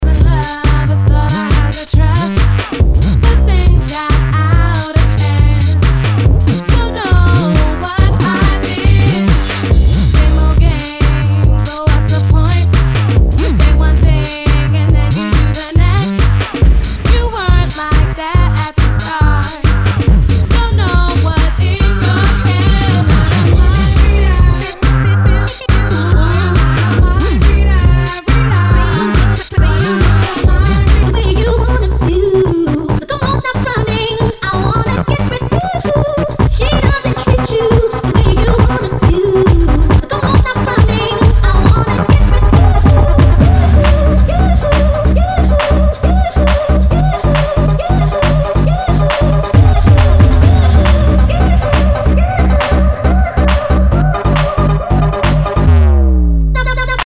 Nu- Jazz/BREAK BEATS
ナイス！UK Garage！